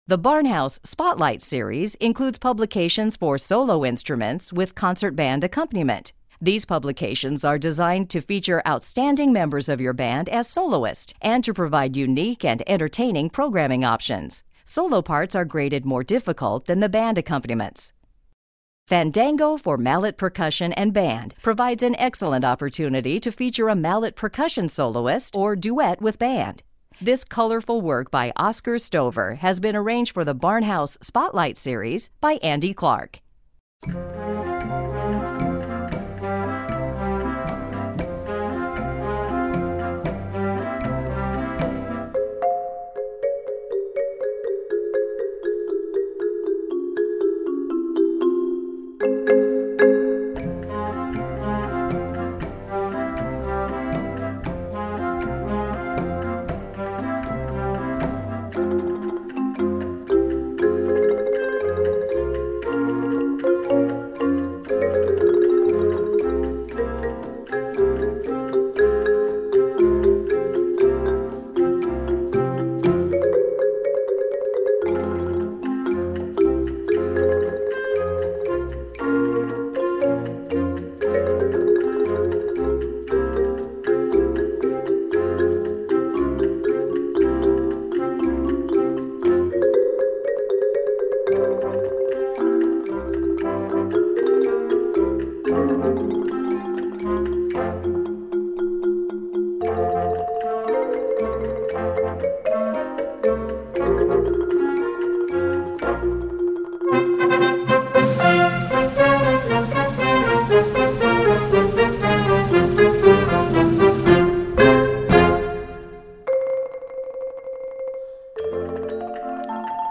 Voicing: Mallet w/ Band